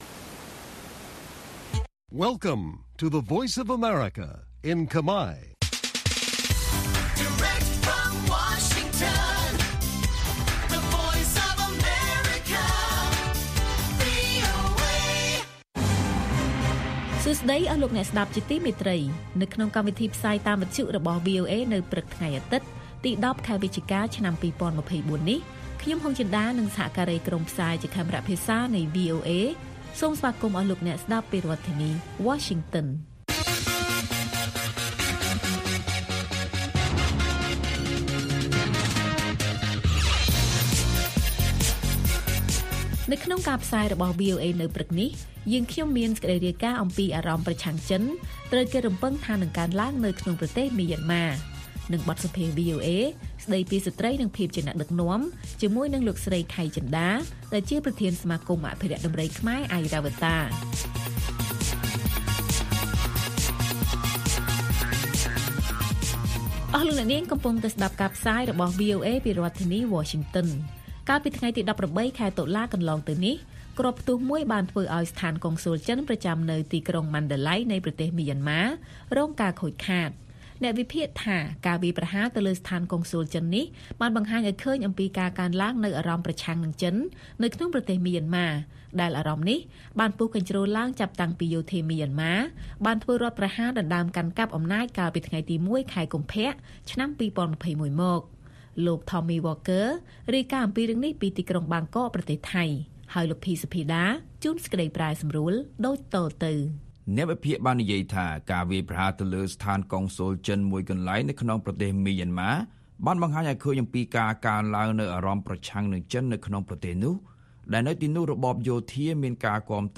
បទសម្ភាសន៍